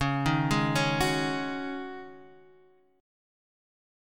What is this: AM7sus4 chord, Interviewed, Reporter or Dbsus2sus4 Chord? Dbsus2sus4 Chord